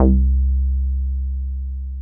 T B303贝司 " T B3贝司03
描述：原始的Roland TB303机器通过Manley话筒前置采样。
Tag: 3 低音 TB303